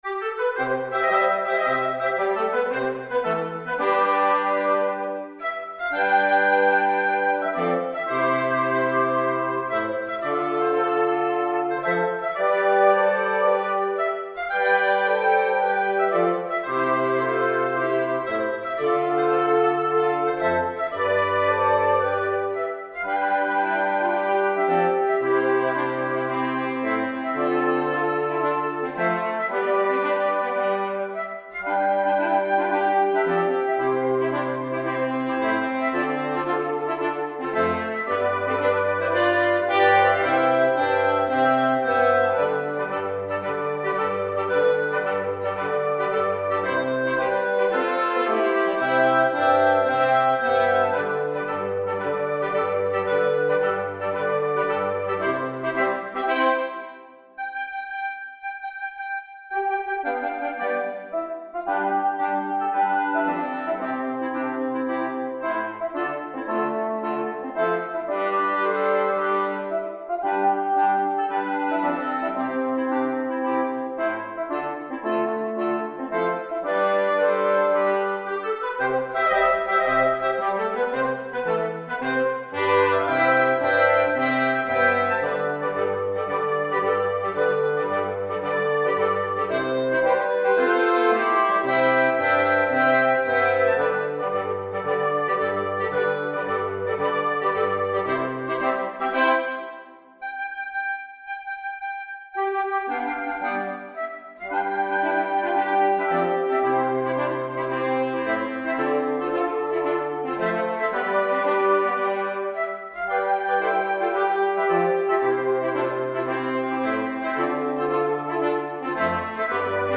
Gattung: Für Holzbläser Quintett